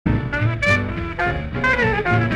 1953/Bandbox, New York NY